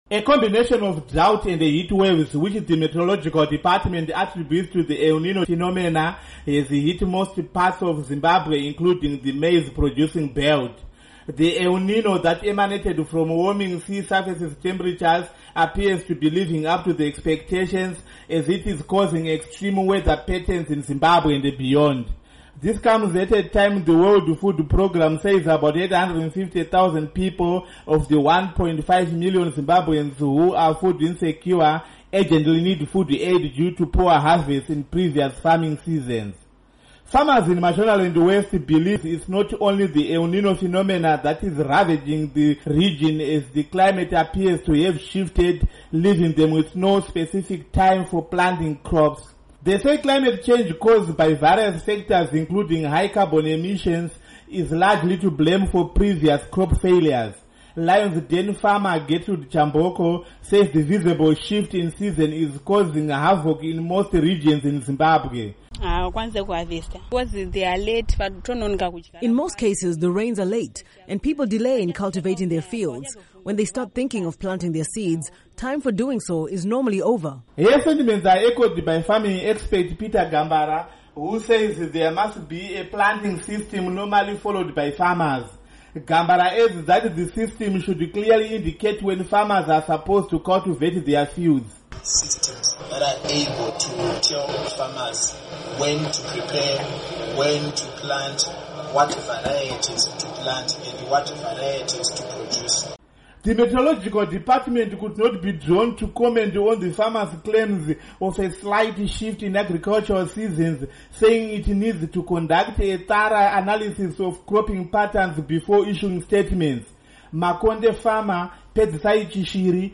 Report on El Nino Devastation